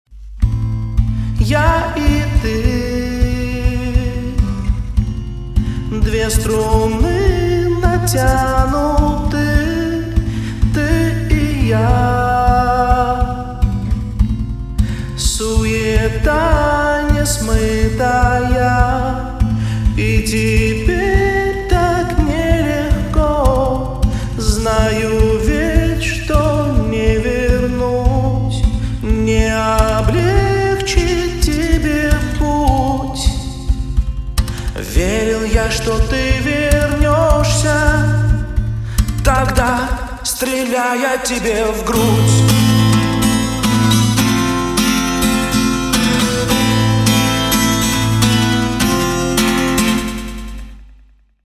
• Качество: 320, Stereo
гитара
мужской голос
эхо